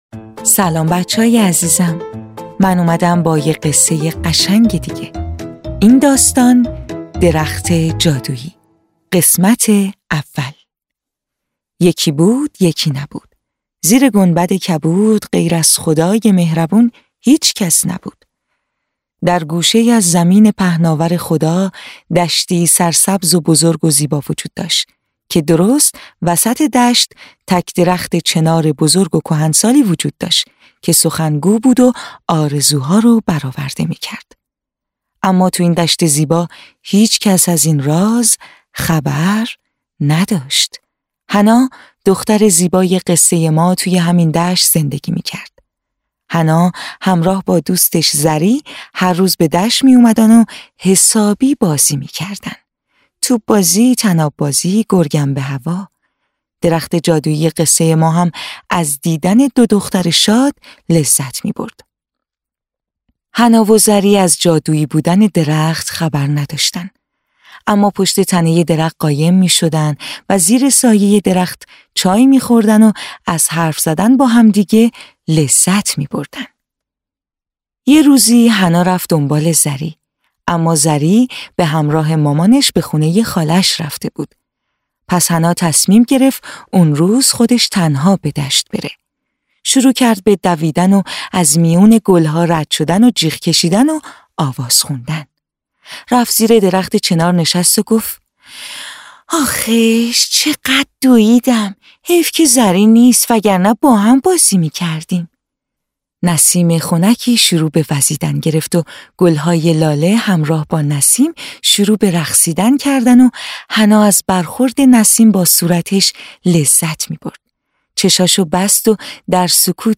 قصه‌های کودکانه صوتی – این داستان: درخت جادویی (قسمت اول)
روایت آرام پیش می‌رود و فرصت می‌دهد کودک با فضا همراه شود، فکر کند و منتظر بماند.
تهیه شده در استودیو نت به نت